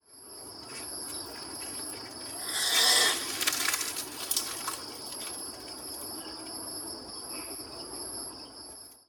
Common (Sumatran) Palm Civet  Paradoxurus musangus
Play call
common-palm-civet_DLY.mp3